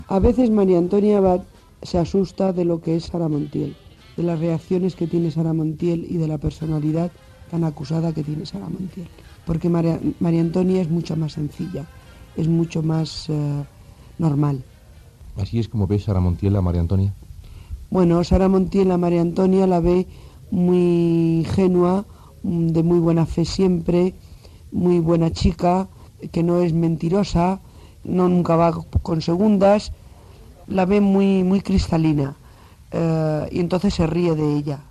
Entrevista a l'actriu Sara Montiel (María Antonia Abad) poc després de ser nomenda filla predilecta de Campo de Criptana (Ciudad Real)